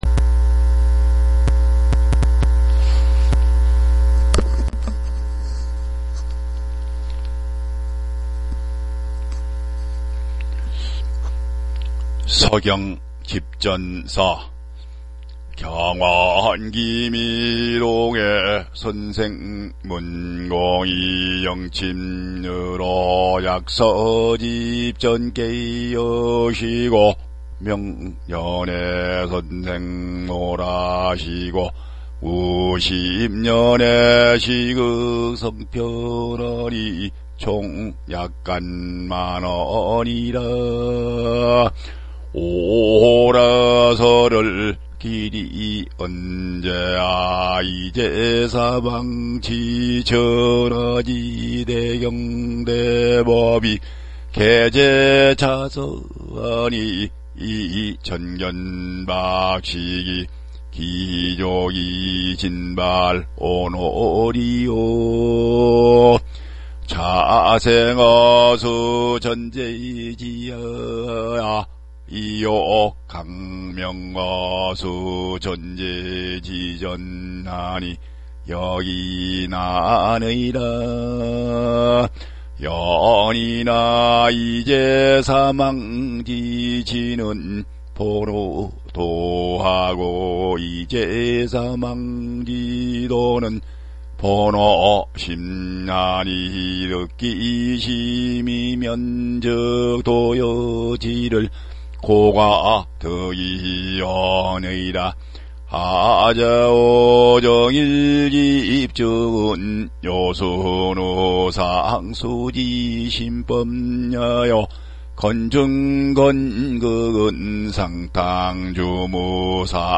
書經集傳 序 (성독)